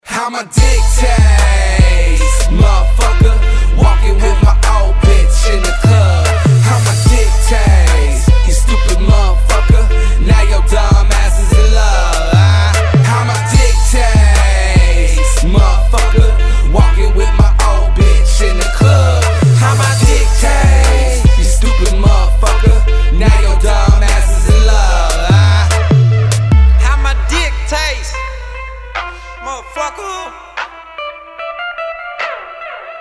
• Качество: 192, Stereo